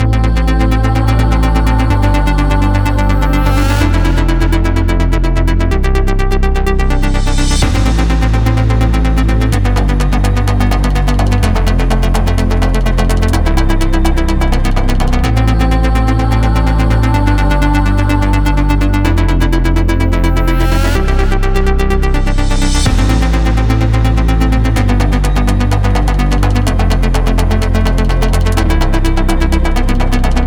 Trance песочница (крутим суперпилы на всём подряд)
В стиле Ферриковской гориеллы пытался наваять: